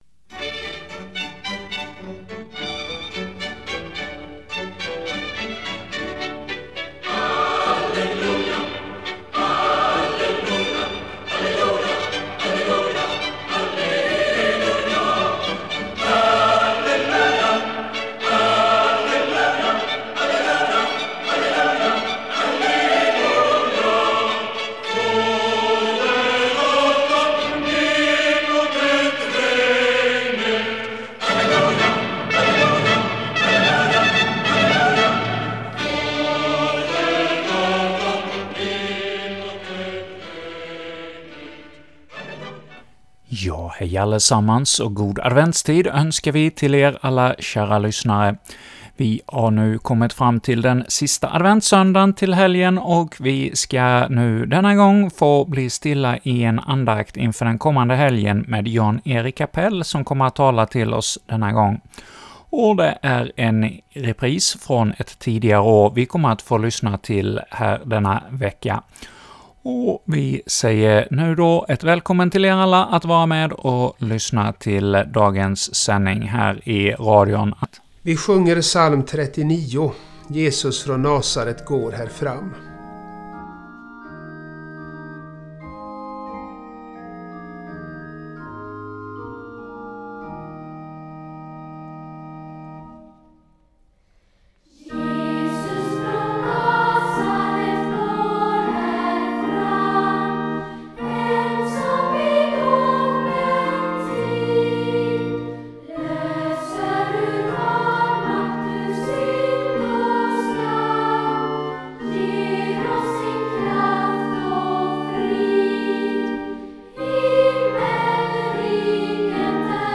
andakt